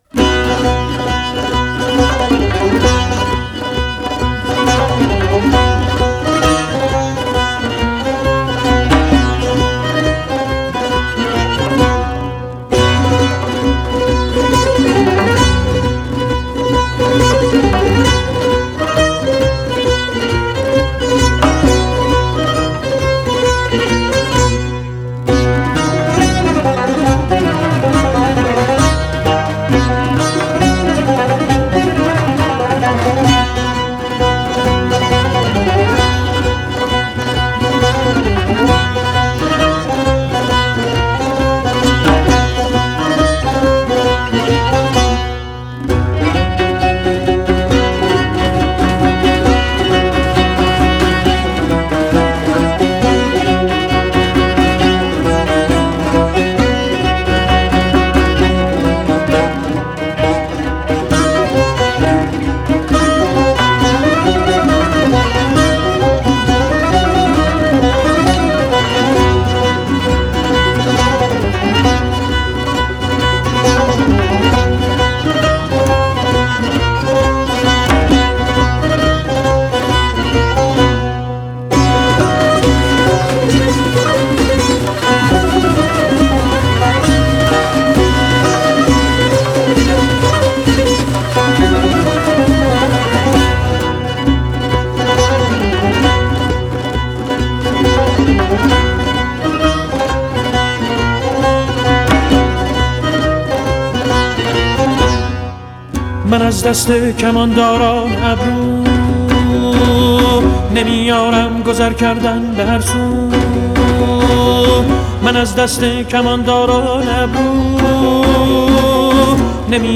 Tasnif Chahargah